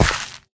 Sound / Minecraft / step / gravel4.ogg
gravel4.ogg